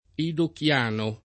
vai all'elenco alfabetico delle voci ingrandisci il carattere 100% rimpicciolisci il carattere stampa invia tramite posta elettronica codividi su Facebook edochiano [ edok L# no ] etn. — nome lett. degli abitanti di Tokyo (dal villaggio di Edo , nucleo originario della città) — non edokiano